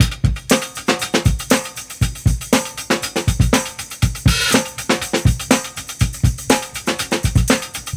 Drum Loop Free MP3 Download | Mingo Sounds
Funky-beat.wav